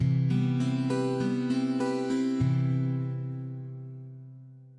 These samples were created using a variety of hardware and software synthesizers and external third party effects.
声道立体声